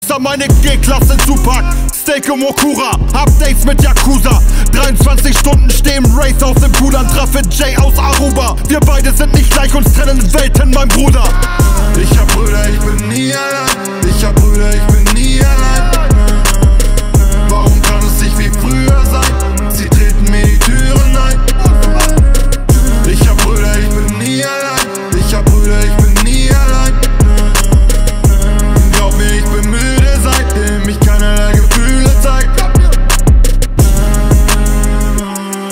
Kategorie Rap/Hip Hop